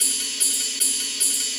Ride 03.wav